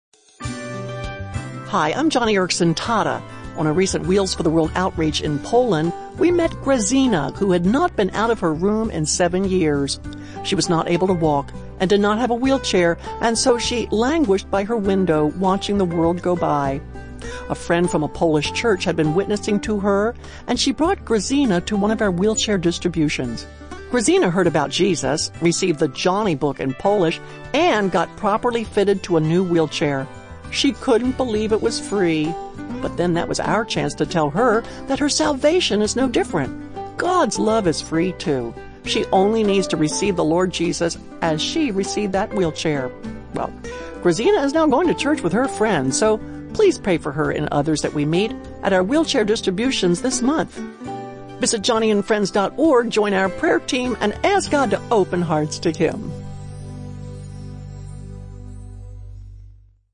By |Published On: November 9, 2019|Categories: 1-Minute Radio Program|